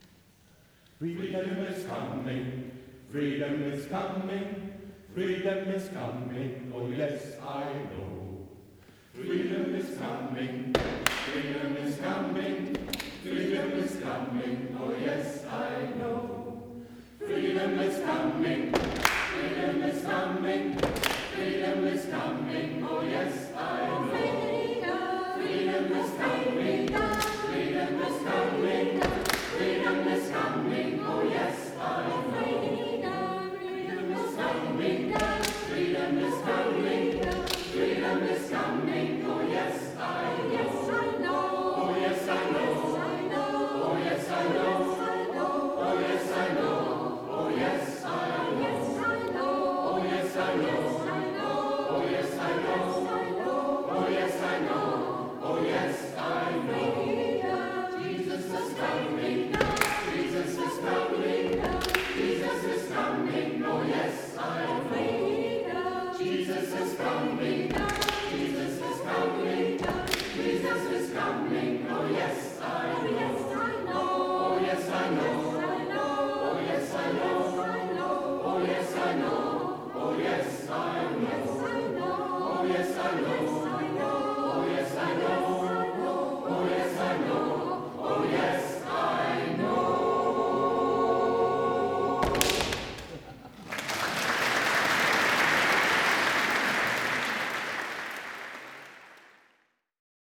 Chorkonzerte
Freedom Is Coming (Konzert im Antoniushaus)